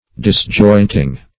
disjointing.mp3